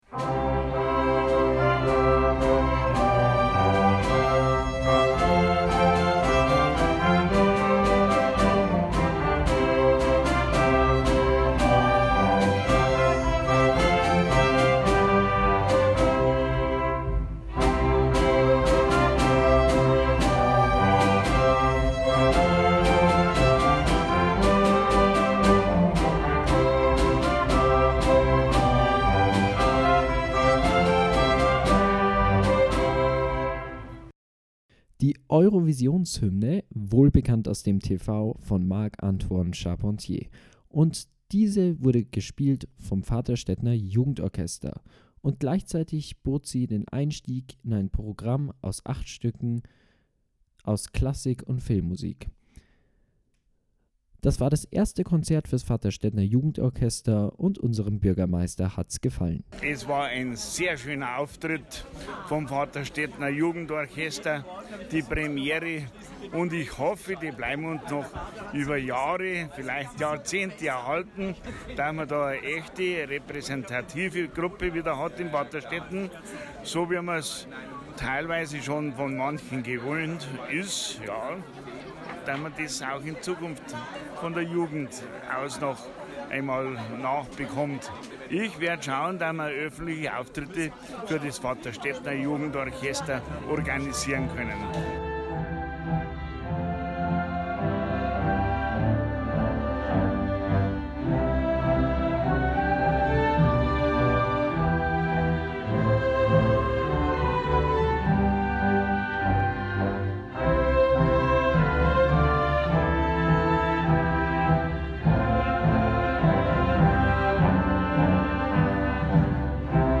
An fünf Tagen probten Jugendliche zwischen elf und 18 insgesamt 8 Stücke für die Aufführung ein. Beim Publikum kam das Konzert gut an.